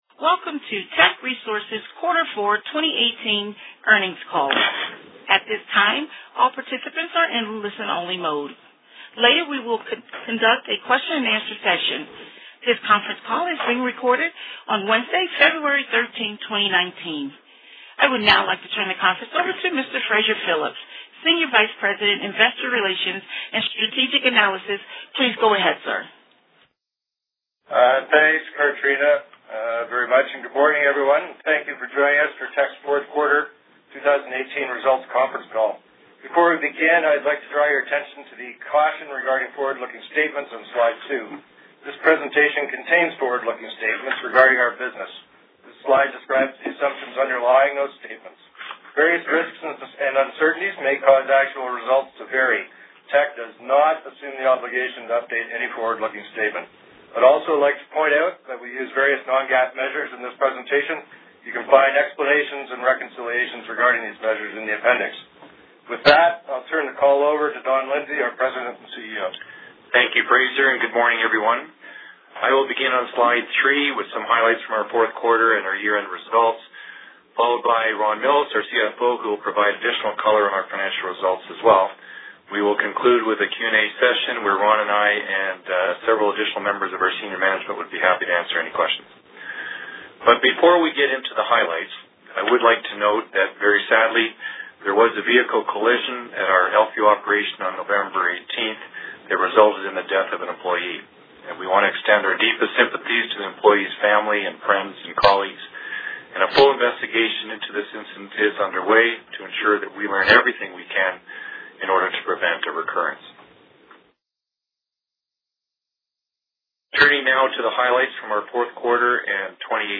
Q4-2018-Financial-Report-Conference-Call-Audio.mp3